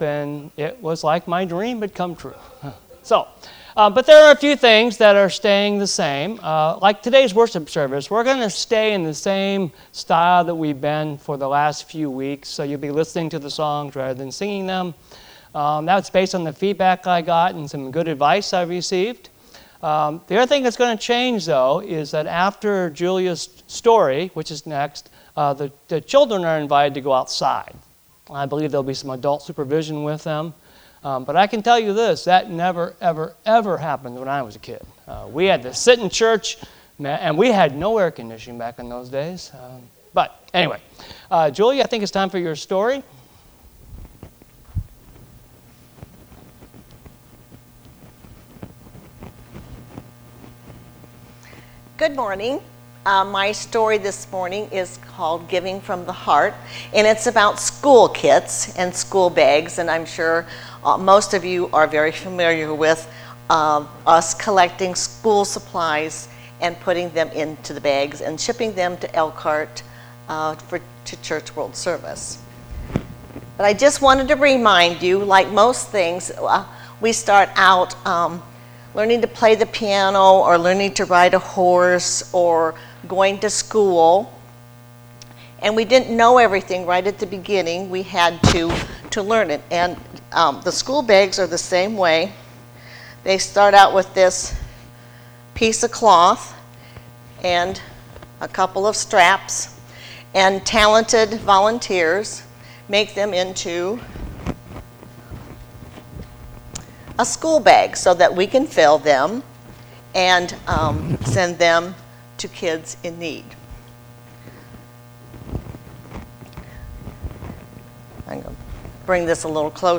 Because of technical difficulties this week, we can only provide the audio of the service.